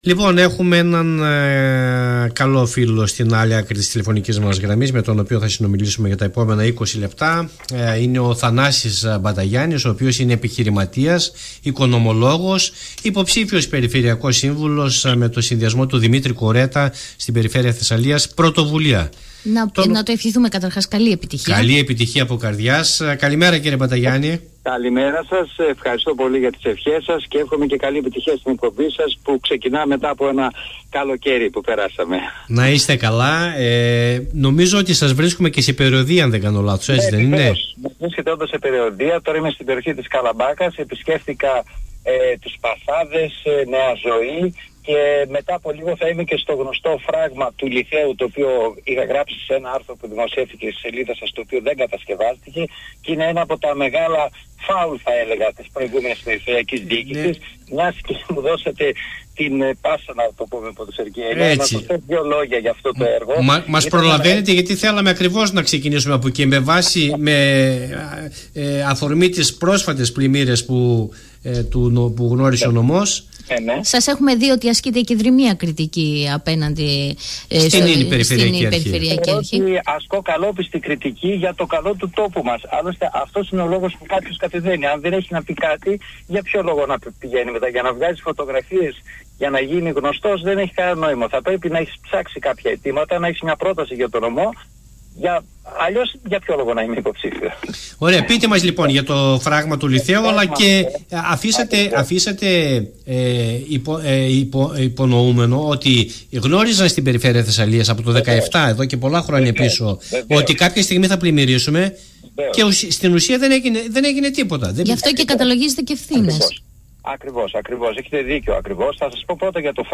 Μια ενδιαφέρουσα συνέντευξη για όλες τις πολιτικές και όχι μόνο εξελίξεις